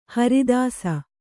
♪ haridāsa